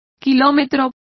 Complete with pronunciation of the translation of kilometres.